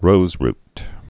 (rōzrt, -rt)